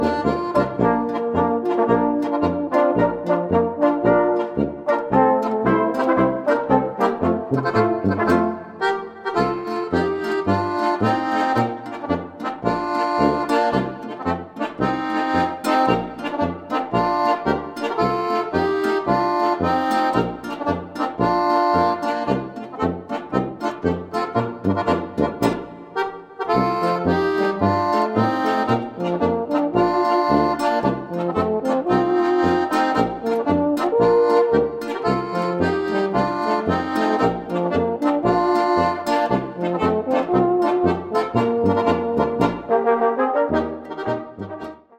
Gattung: Volksmusik natürlicher und ursprünglicher Art
Besetzung: Volksmusik/Volkstümlich Weisenbläser